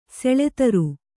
♪ seḷe taru